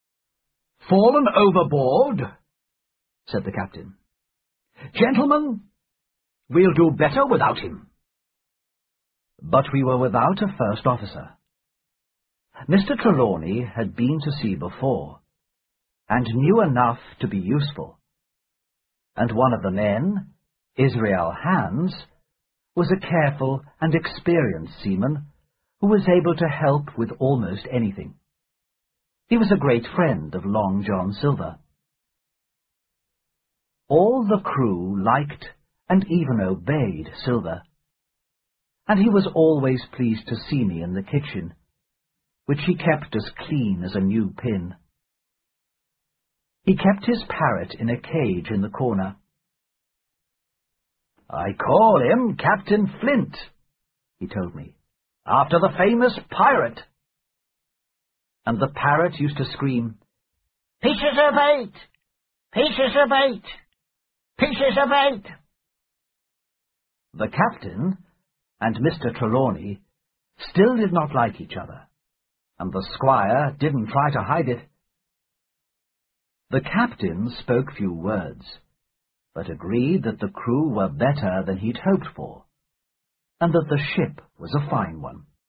在线英语听力室《金银岛》第七章 苹果桶(2)的听力文件下载,《金银岛》中英双语有声读物附MP3下载